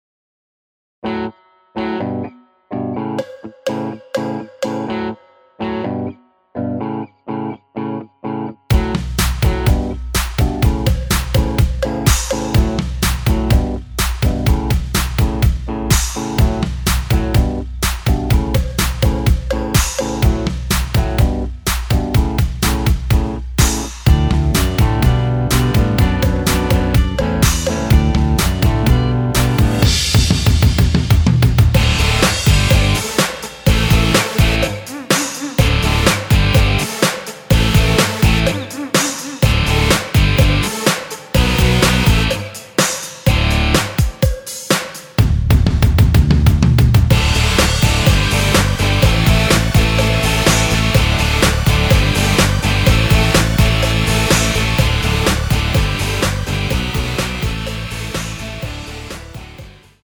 (-2)내린 MR 입니다.
◈ 곡명 옆 (-1)은 반음 내림, (+1)은 반음 올림 입니다.
앞부분30초, 뒷부분30초씩 편집해서 올려 드리고 있습니다.
중간에 음이 끈어지고 다시 나오는 이유는